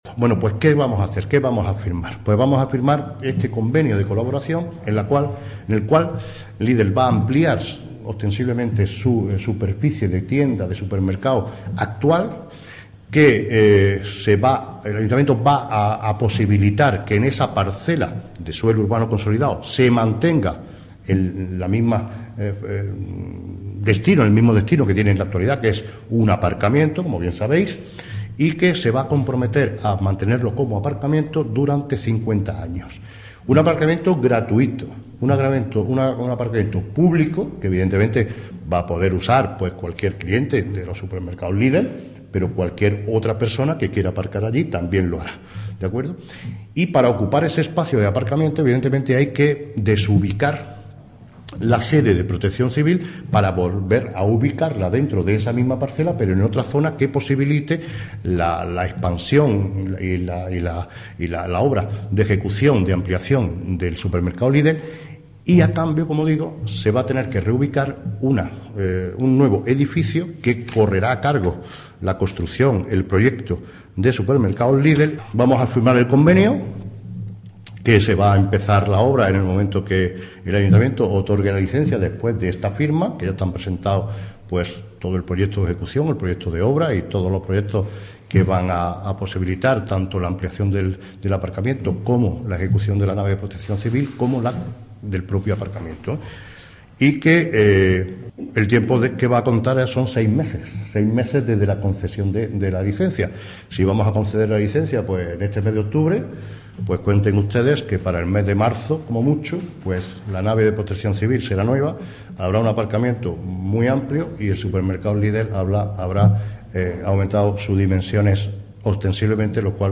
Cortes de voz M. Barón 904.16 kb Formato: mp3